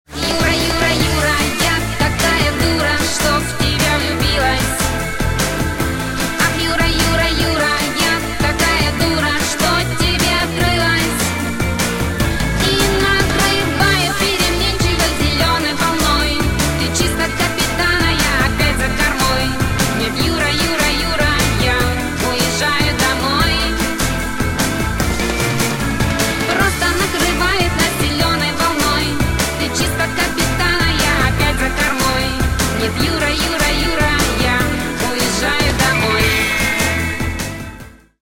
Главная » 2012 » Июнь » 8 » Припев песни.